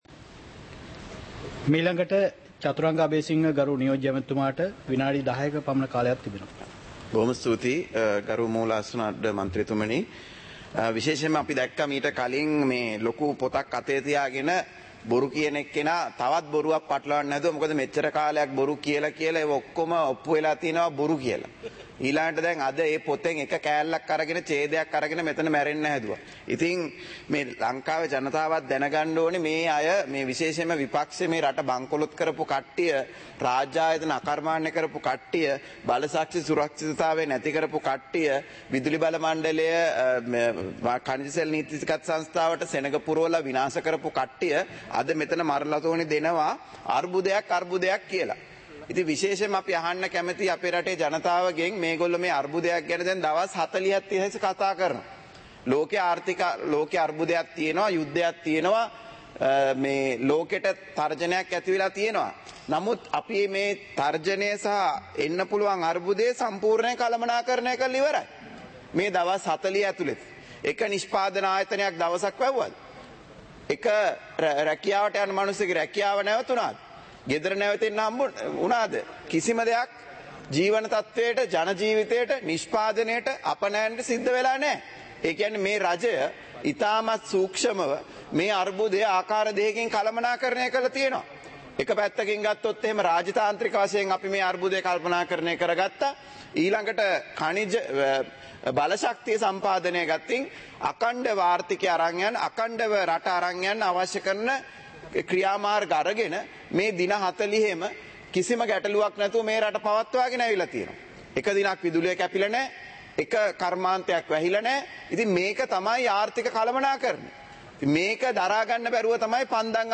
இலங்கை பாராளுமன்றம் - சபை நடவடிக்கைமுறை (2026-04-07)